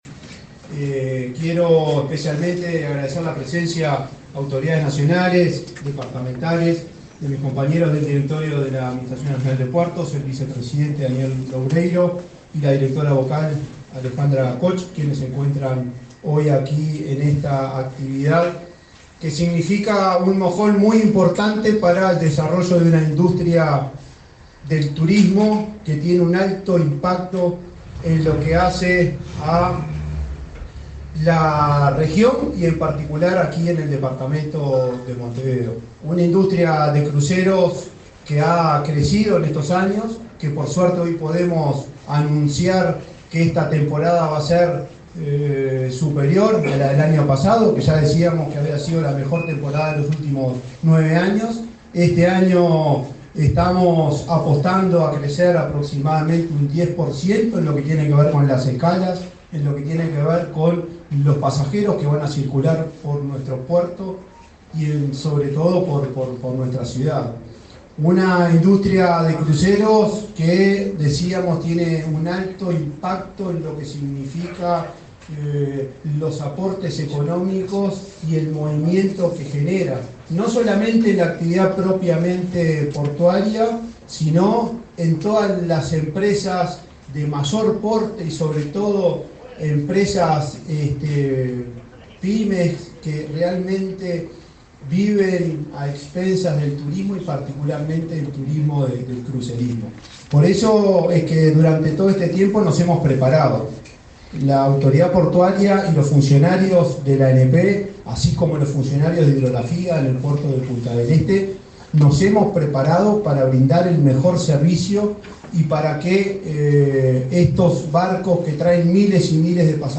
Palabra de autoridades en lanzamiento del Ministerio de Turismo
Este lunes 4 en Montevideo, el presidente de la Administración Nacional de Puertos, Juan Curbelo, y el ministro de Turismo, Tabaré Viera, participaron